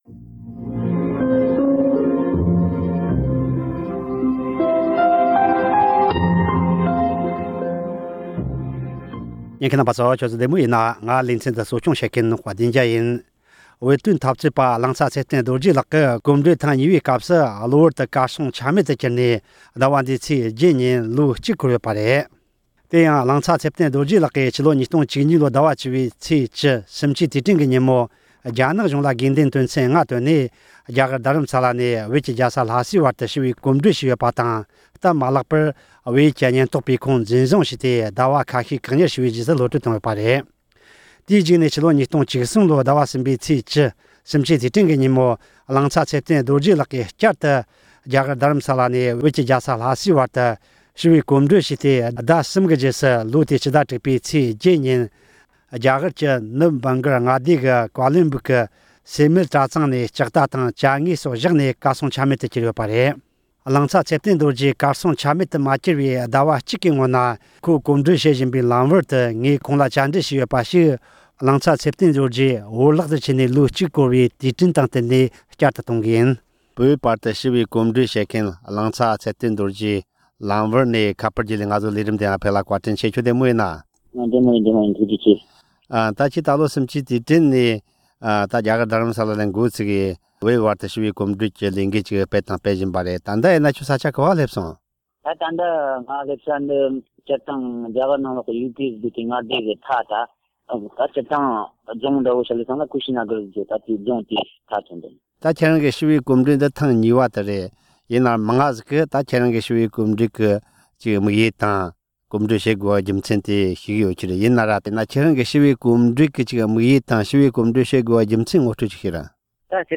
ཁོང་བོར་བརླག་མ་སོང་བའི་ཟླ་གཅིག་གི་སྔོན་གླེང་མོལ་ཞུས་པ་དེ་གསན་རོགས།